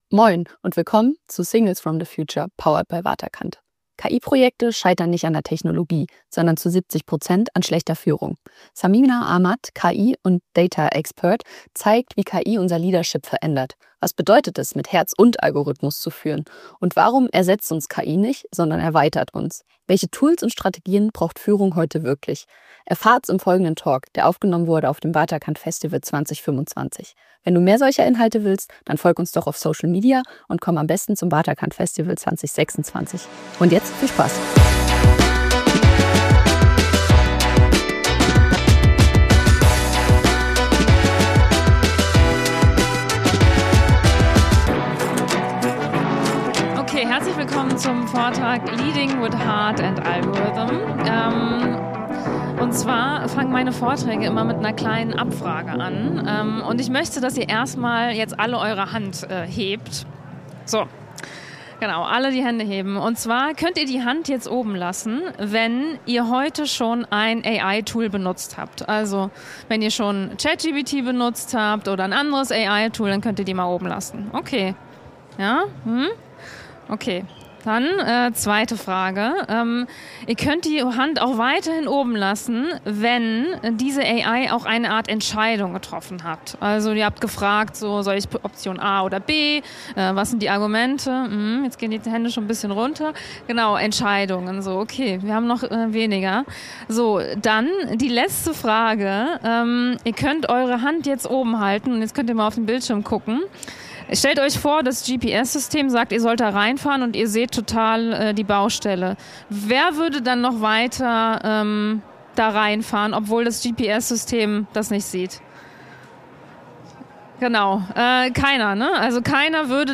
Ein Talk über Verantwortung, Ethik, Empowerment – und darüber, was es heißt, in Zeiten von KI wirklich zu führen. Aufgenommen beim Waterkant Festival 2025.